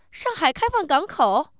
surprise